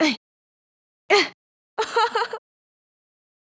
casting_success.wav